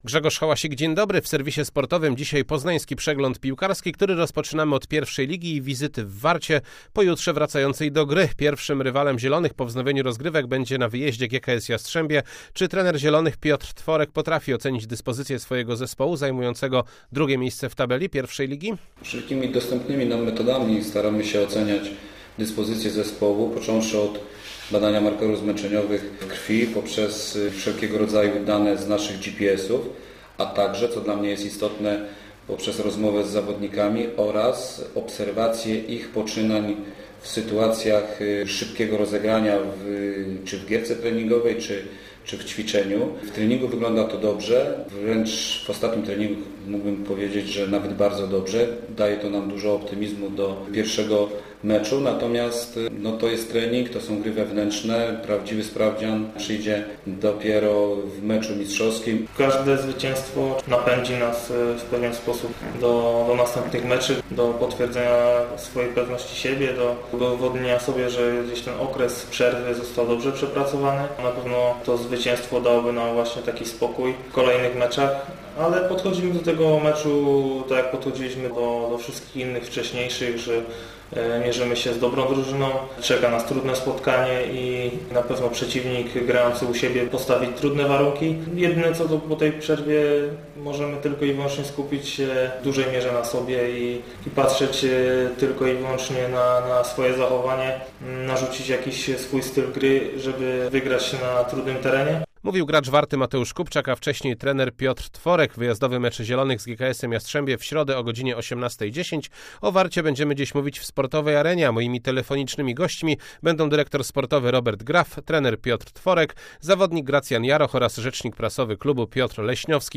01.06. SERWIS SPORTOWY GODZ. 19:05